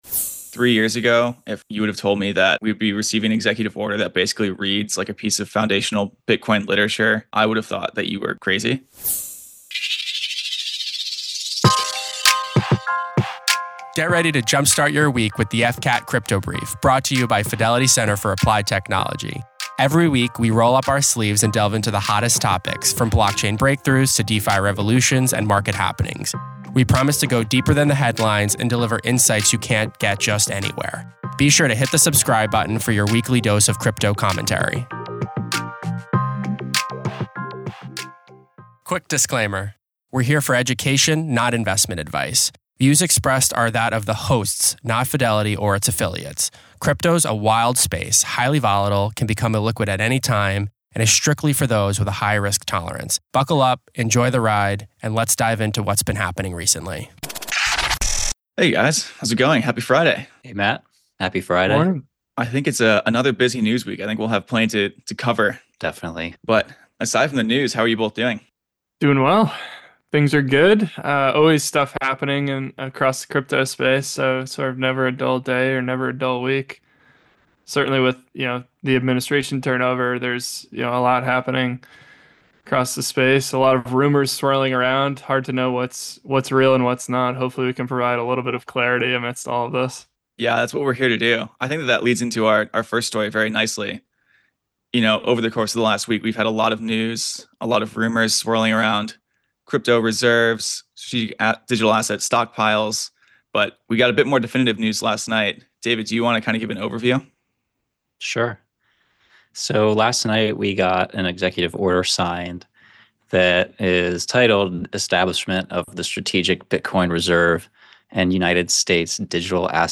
Listen in as our hosts dive into another big week in crypto. On this episode of the Crypto Brief, the team discusses recent updates on DeFi governance and crypto reserve strategies in the U.S. and El Salvador.